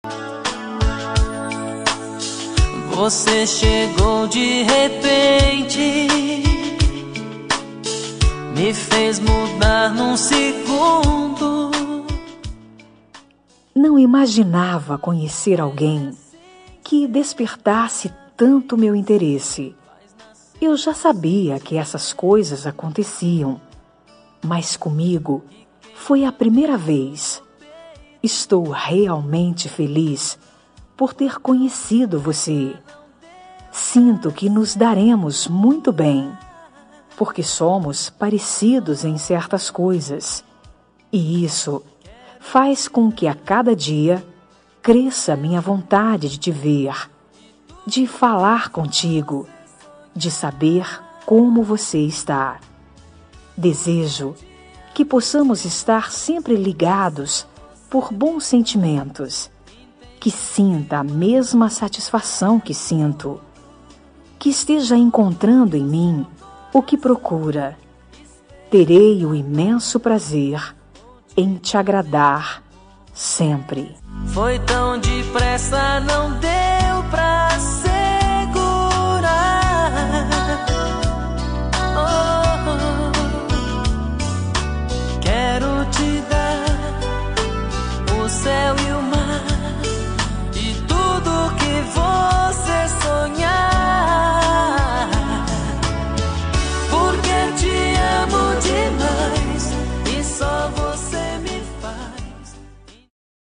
Telemensagem Momentos Especiais – Voz Feminina – Cód: 201876 – Adorei te Conhecer